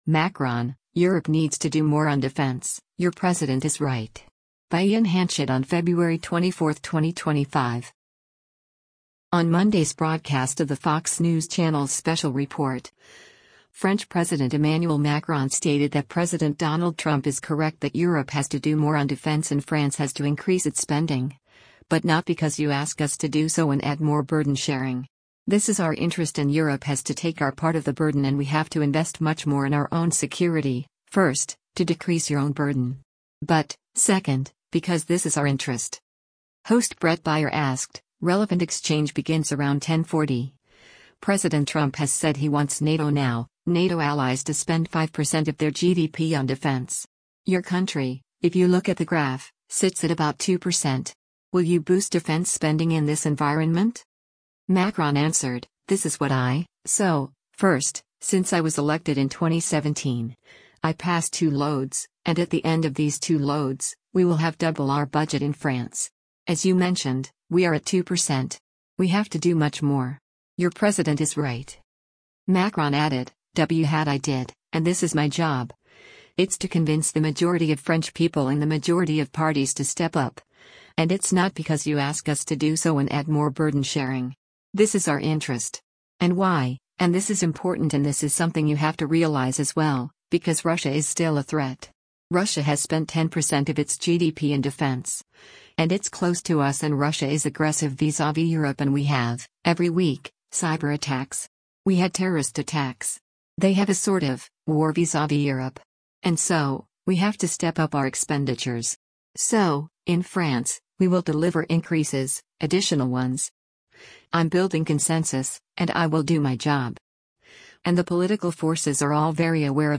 On Monday’s broadcast of the Fox News Channel’s “Special Report,” French President Emmanuel Macron stated that President Donald Trump is correct that Europe has to do more on defense and France has to increase its spending, but “not because you ask us to do so and add more burden sharing. This is our interest” and Europe has “to take our part of the burden and we have to invest much more in our own security, first, to decrease your own burden. But, second, because this is our interest.”